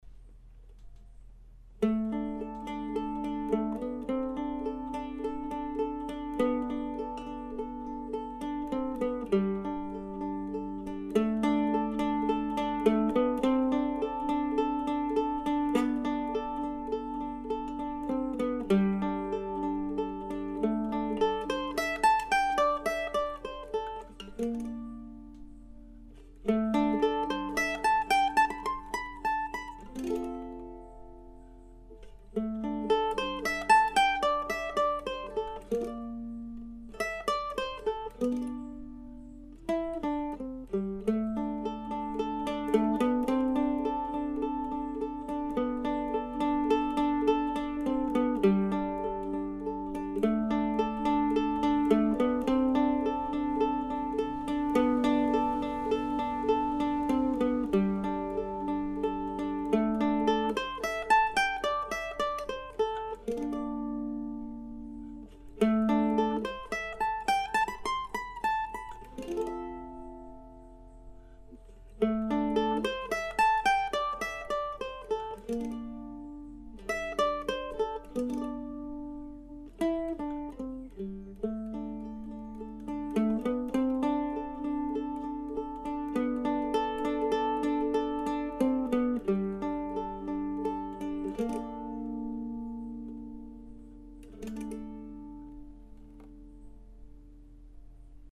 I don't often write for solo mandolin in a "classical" context, although my ongoing series of Deer Tracks pieces are the exception to that rule.